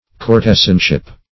Courtesanship \Cour"te*san*ship\